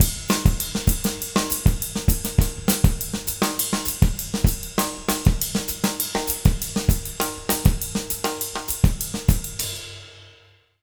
100SONGO03-L.wav